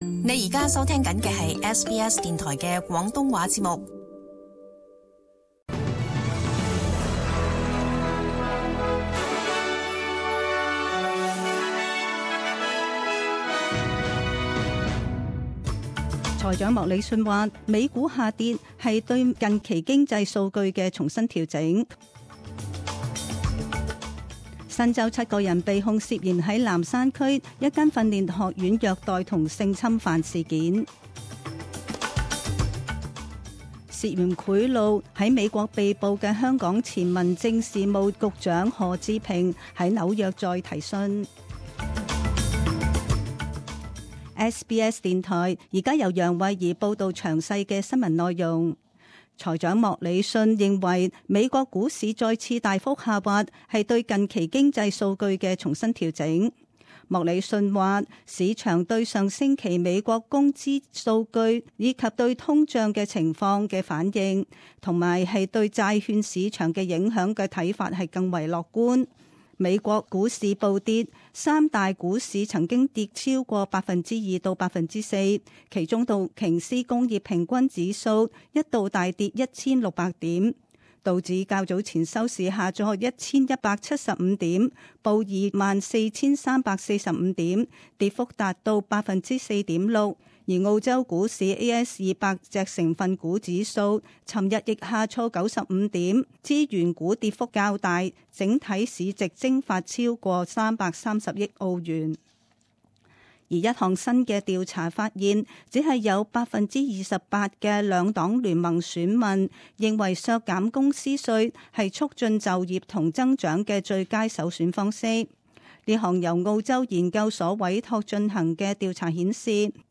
二月六日 [十點鐘新聞 ]
SBS Cantonese 10am news Source: SBS